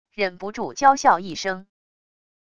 忍不住娇笑一声wav音频